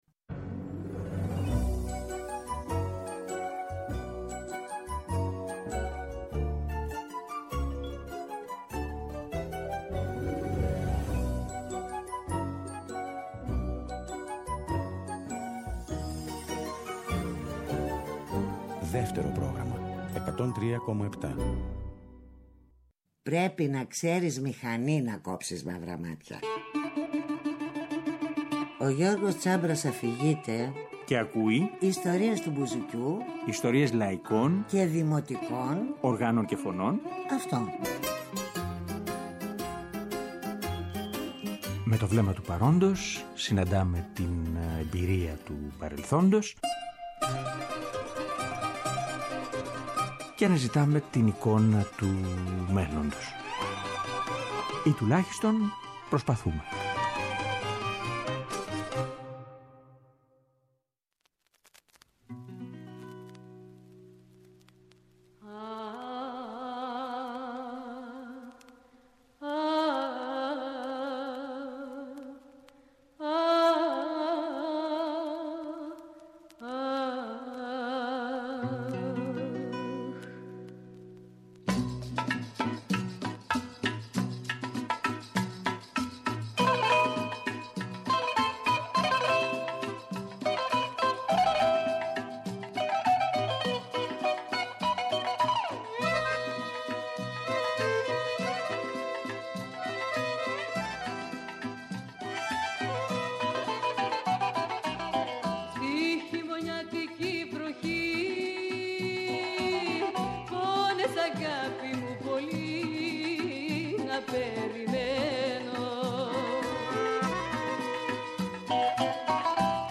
μπουζούκι